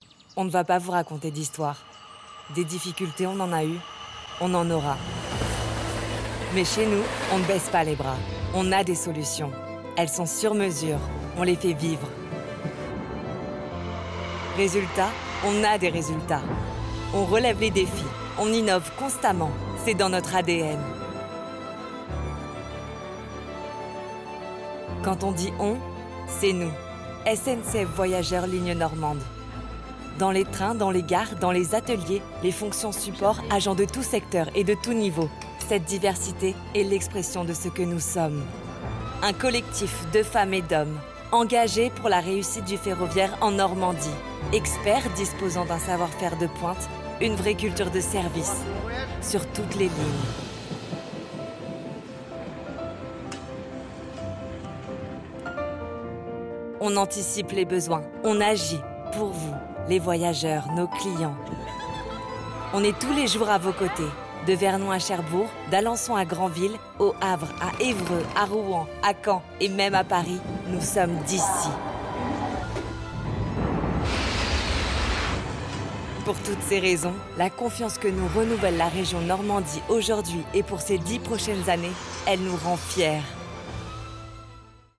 Natural, Llamativo, Versátil, Seguro, Cálida
Corporativo